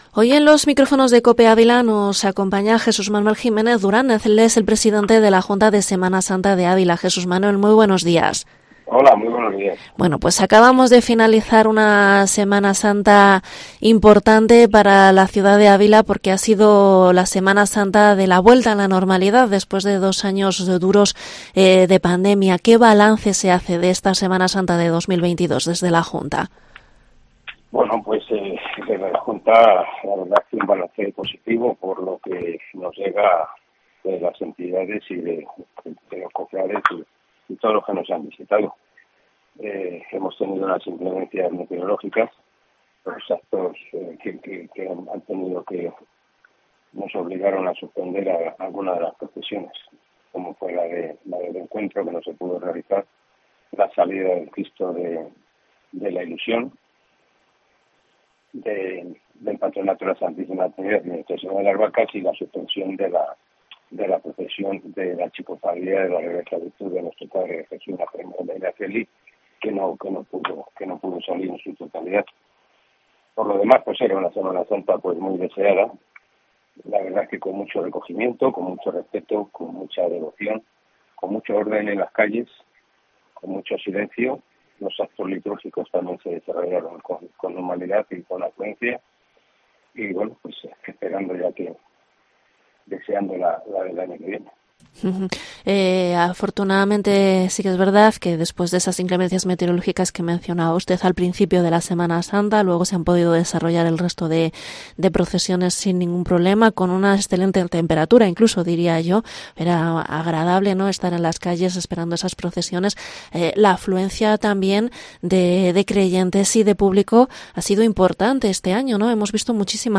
Entrevista presidente Junta Semana Santa. Balance Semana Santa 2022